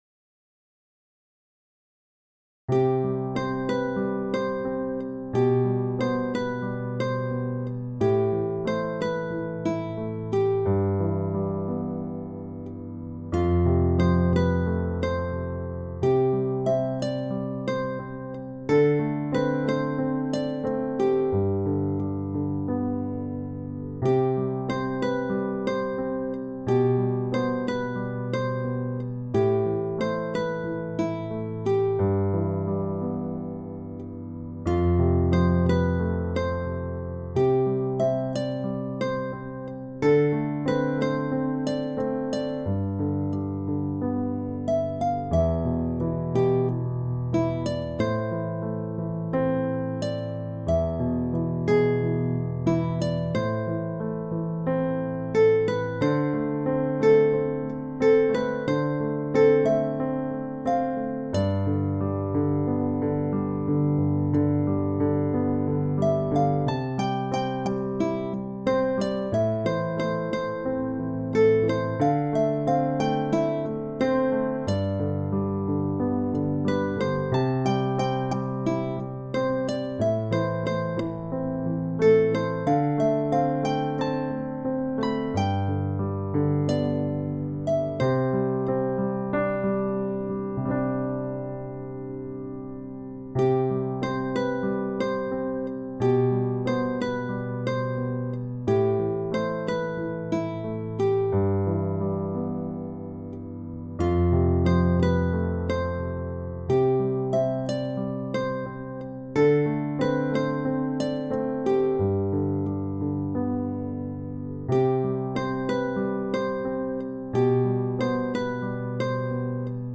吉他曲